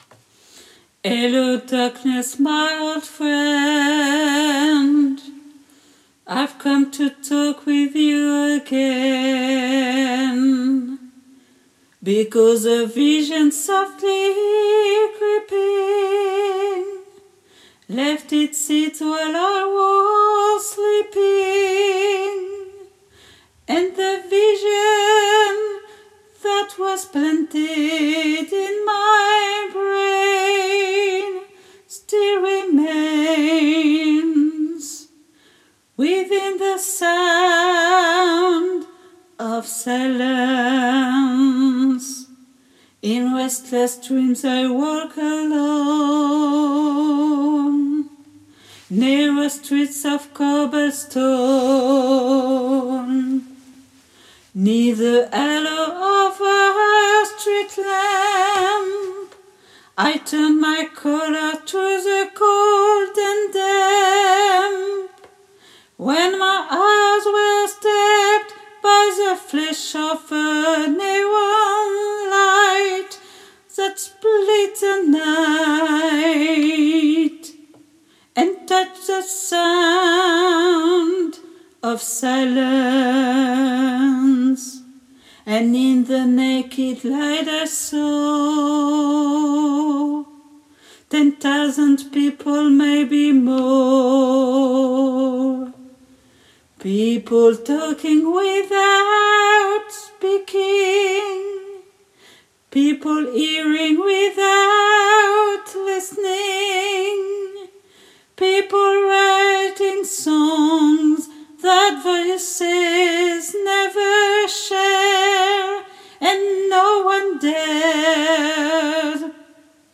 MP3 versions chantées
Hommes